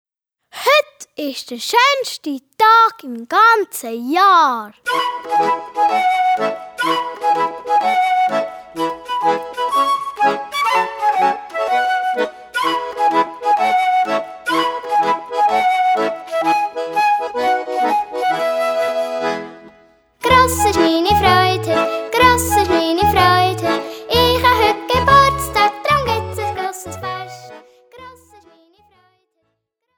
Besetzung: Gesang